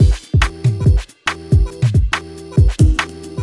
Category 🎵 Music
beat beats drumkit fast Gabber hardcore House Jungle sound effect free sound royalty free Music